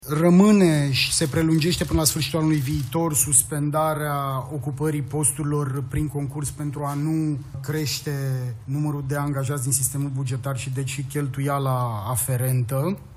Prezentare maraton la Palatul Victoria: vicepremierul Tanczos Barna alături de miniștrii Finanțelor, Muncii, Sănătății și Educației au prezentat măsurile din pachetul fiscal pentru care Guvernul își va asuma răspunderea săptămâna viitoare.